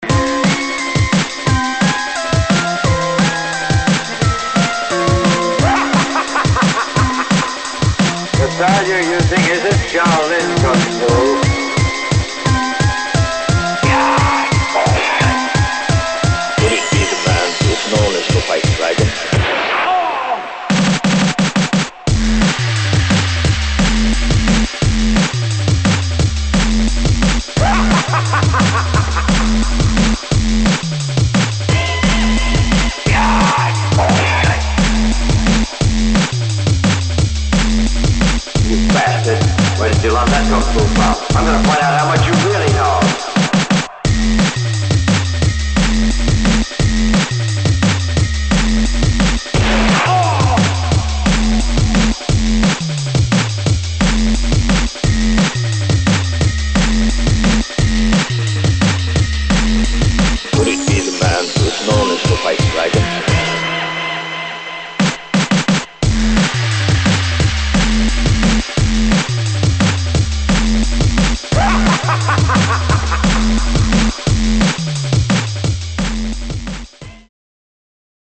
[ DUBSTEP / DRUM'N'BASS ]